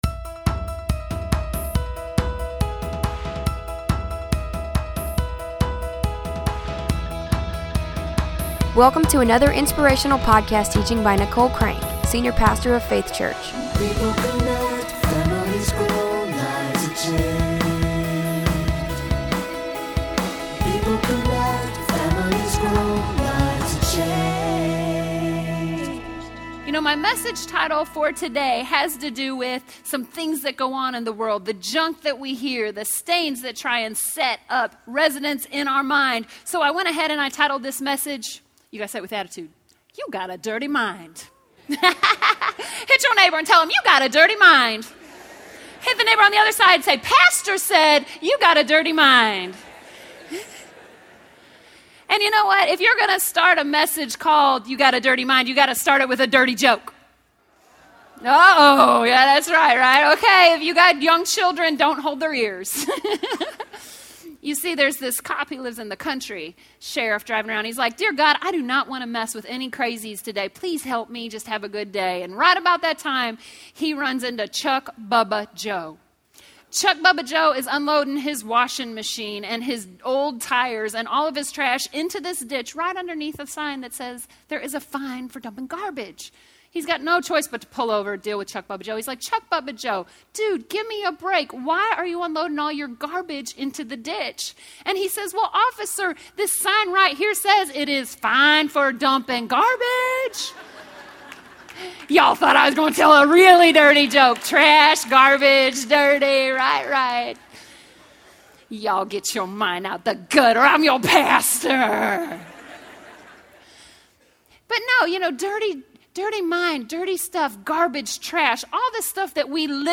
This message will instruct you on how to wash, renew, and change your mind.